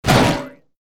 KART_Hitting_Wall.ogg